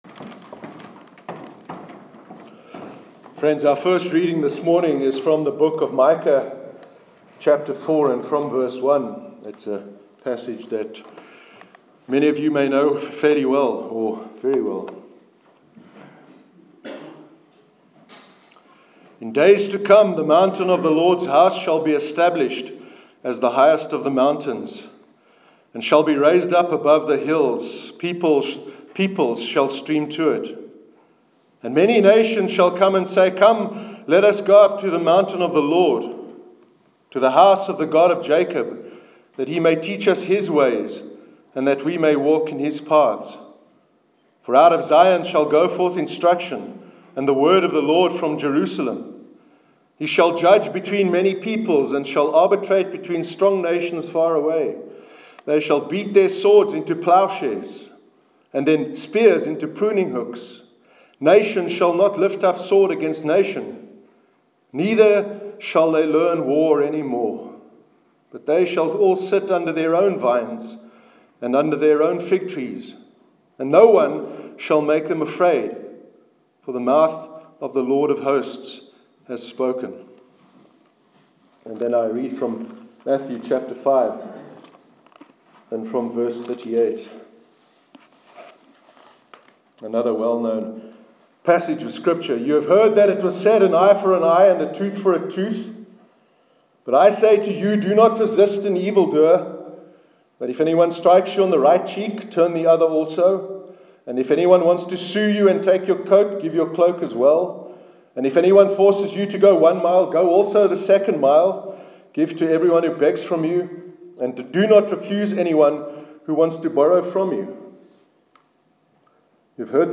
Sermon on the Myth of Redemptive Violence, part one, 30 April 2017